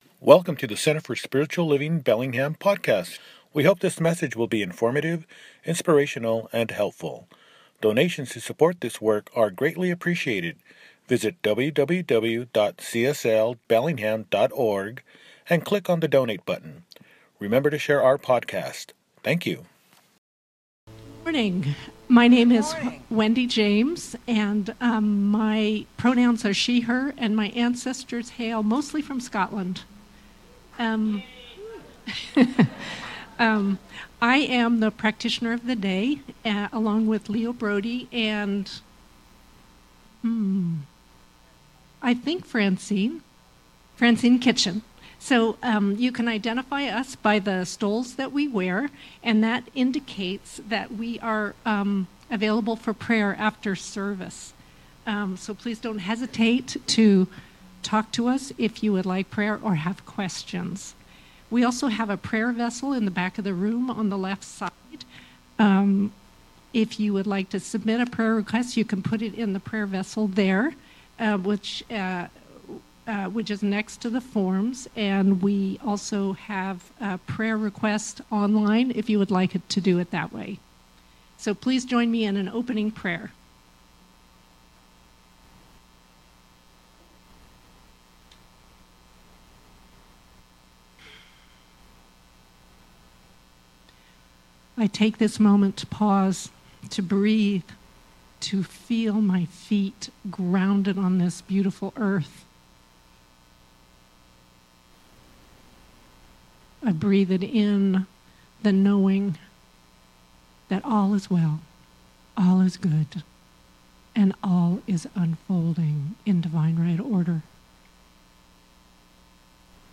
Wanna Change the World? Do It! – Celebration Service
Mar 15, 2026 | Podcasts, Services